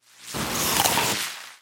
sfx
bug_chomp.mp3